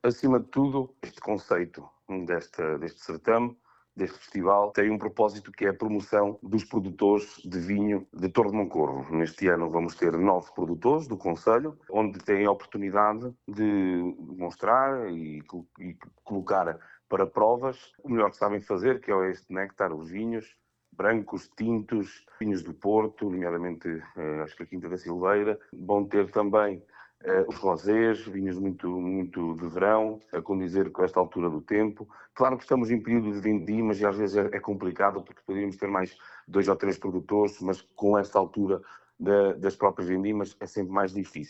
A Praia Fluvial da Foz do Sabor, em Torre de Moncorvo, volta a receber mais uma edição do Vinho Sabor Douro, no próximo fim de semana, de 13 a 14 de setembro, que pretende sobretudo promover os vinhos da região, como explica José Meneses, presidente da câmara municipal de Torre de Moncorvo: